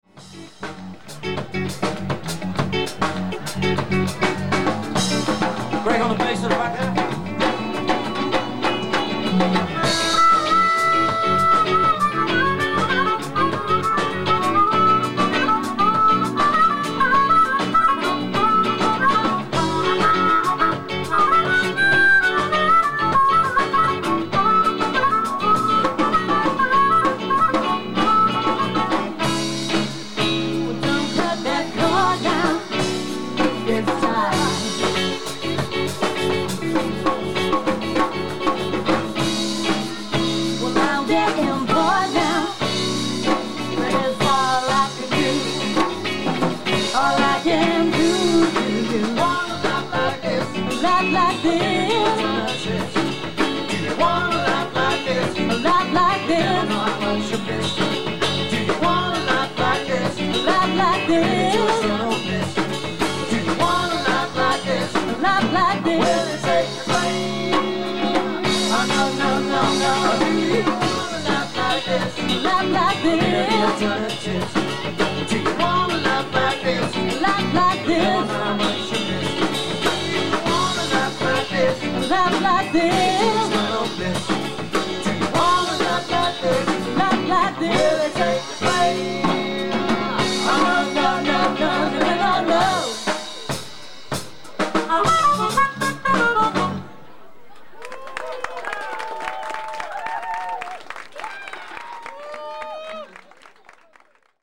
This number is a really great dance track.